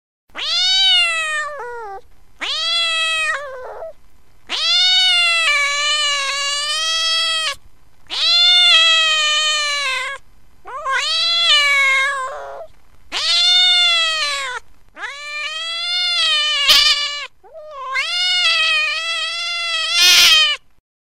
Cat Ringtone
cat_sound.mp3